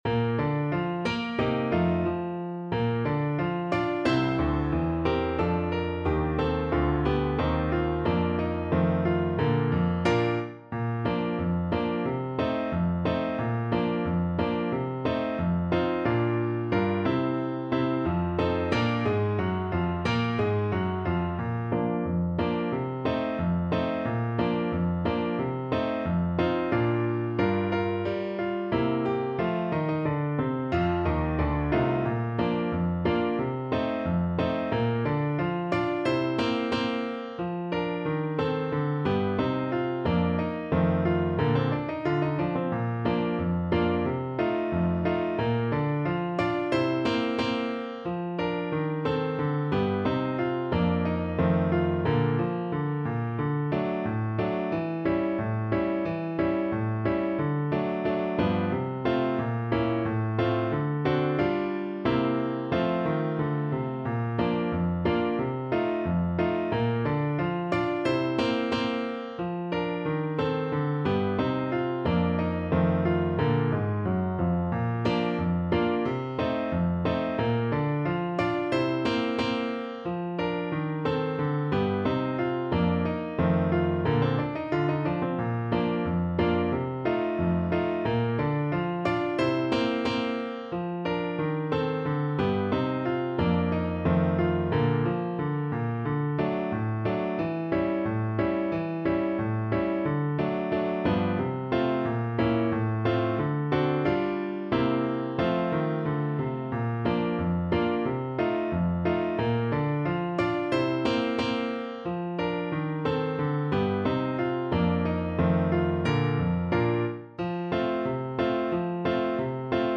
2/2 (View more 2/2 Music)
=90 Fast and cheerful